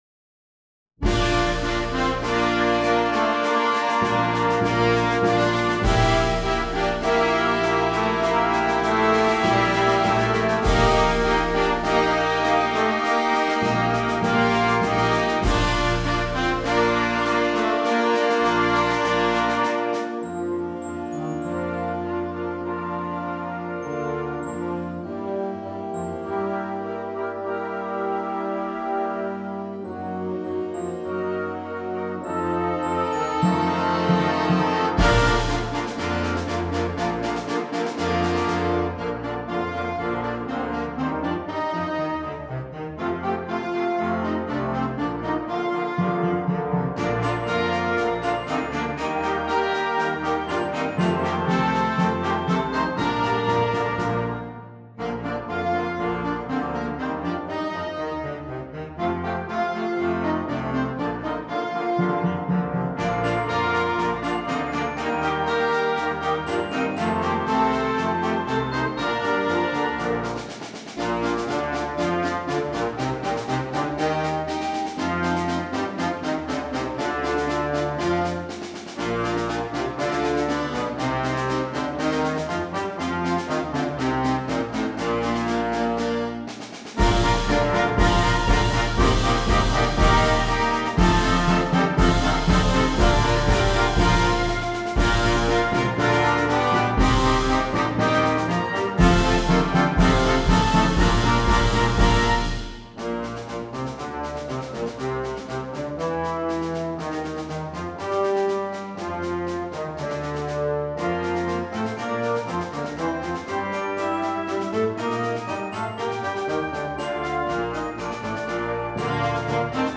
• Flauta
• Oboe
• Clarinete en Bb 1
• Clarinete Bajo
• Saxofón Alto 1
• Saxofón Tenor
• Saxofón Barítono
• Trompeta en Bb 1
• Corno en F
• Trombón 1
• Eufonio/Fagot
• Tuba
• Timbal
• Xilófono
• Triangulo
• Platillos
• Redoblante
• Bombo